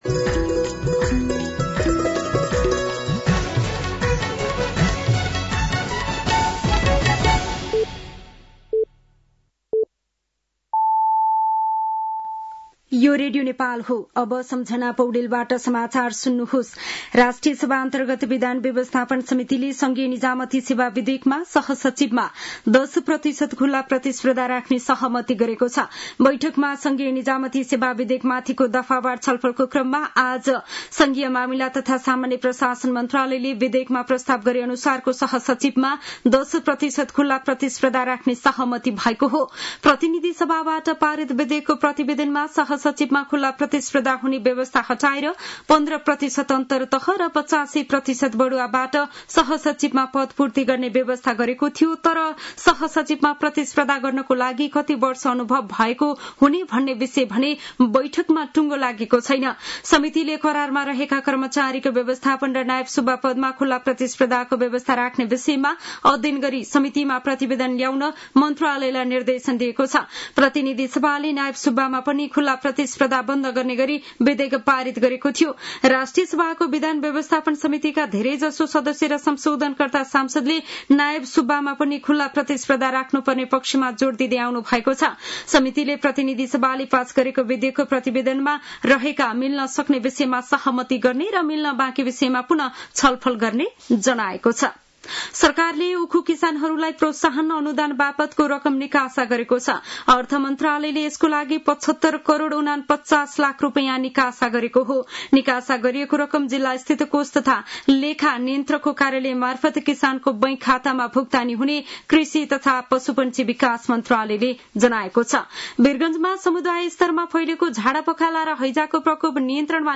साँझ ५ बजेको नेपाली समाचार : ११ भदौ , २०८२